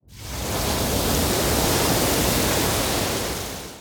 Free Fantasy SFX Pack
Wave Attack 2.ogg